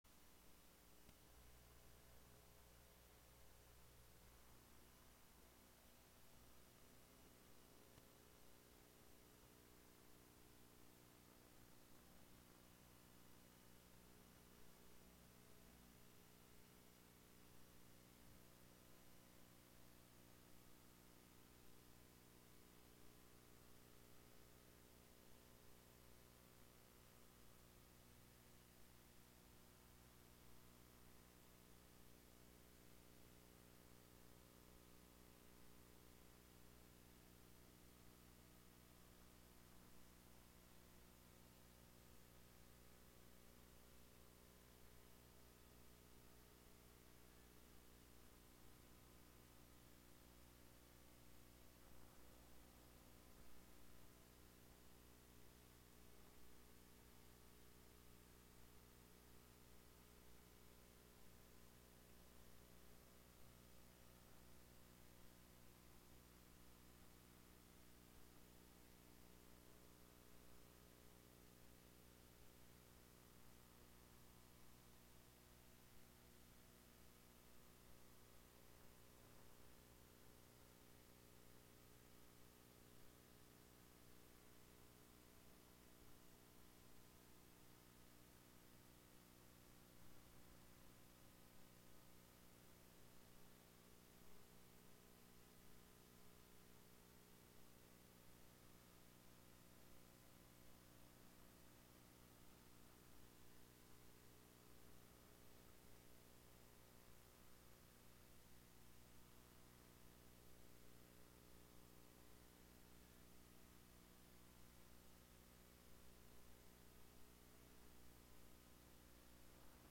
Live from the Catskill Clubhouse.